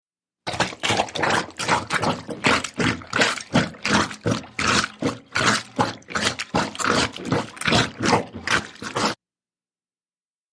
HOUSEHOLD SUCTION: INT: Rhythmic suction, regular and close up plumbers helper on laundry.